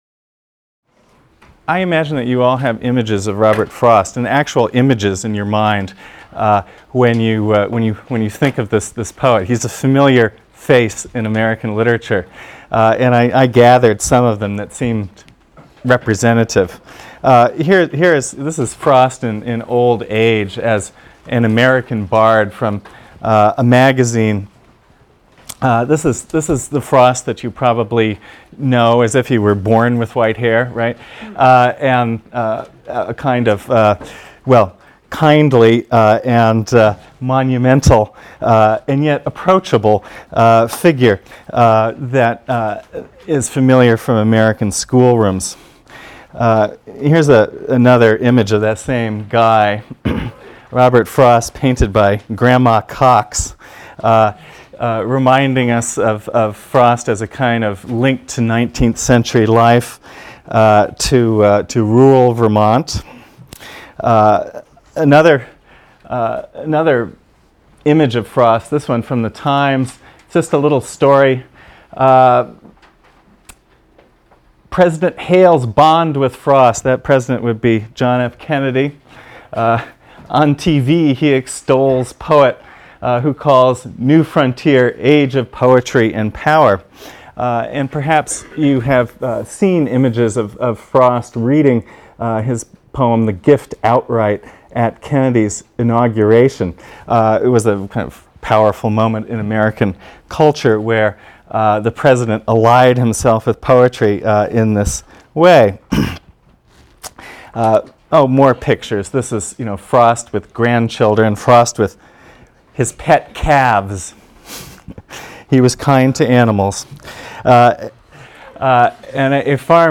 ENGL 310 - Lecture 2 - Robert Frost | Open Yale Courses